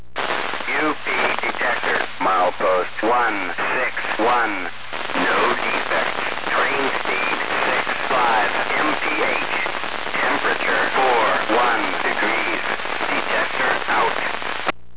I used a Panasonic RN-402 mini-cassette recorder hooked directly to my ham radio (Yaesu FT-411E) or Uniden scanner (BC-200XLT) to record.
Detectors Heard From My House
This detector is rarely heard and very dependent on band conditions.